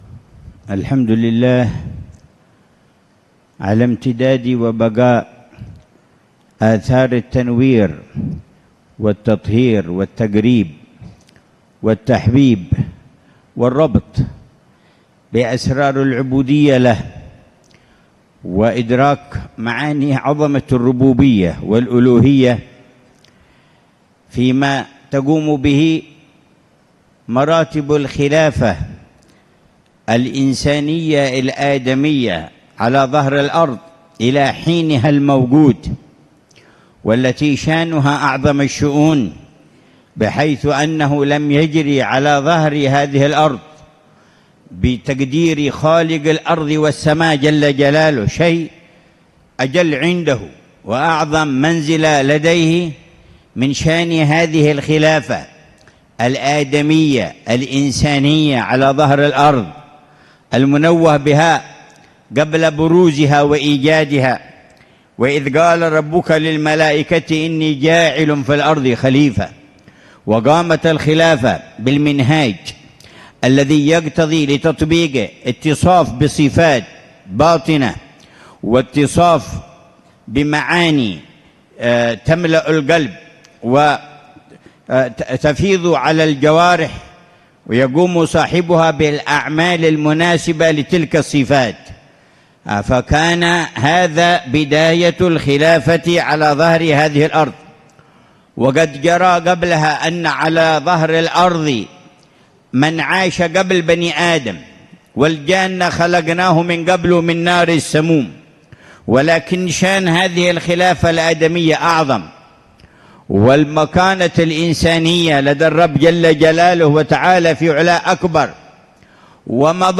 كلمة العلامة الحبيب عمر بن حفيظ في الذكرى الثالثة لوفاة الحبيب أبي بكر العدني بن علي المشهور رحمه الله، في مسجد الإمام المهاجر، بمنطقة الحسيسة - وادي حضرموت، ليلة الأربعاء 29 ذو الحجة 1446هـ